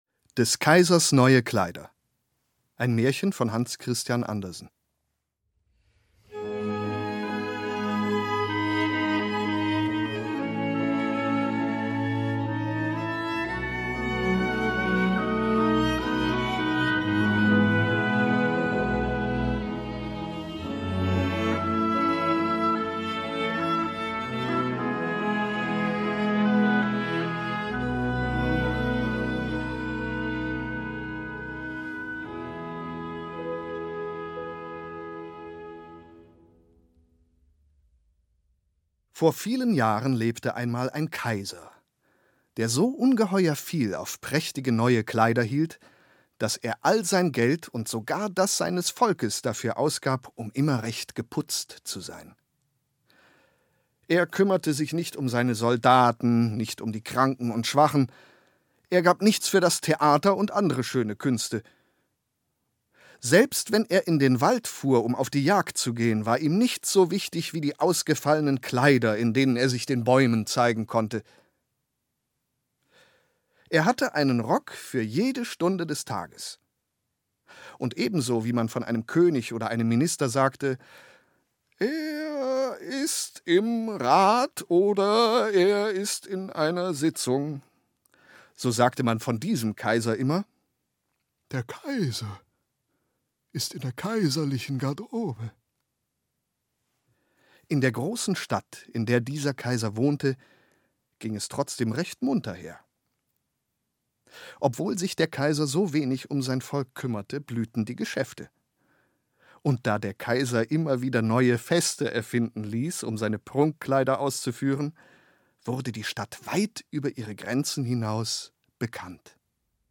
Musik für Salonorchester
Violine
Viola
Cello
Flöte
Oboe
Klarinette
Kontrabass
Klavier/Celesta